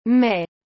O nome do mês de maio em francês é mai, e a pronúncia correta é [mɛ], bem parecida com “mê” em português, mas sem aquele som anasalado.
O som é curto e direto.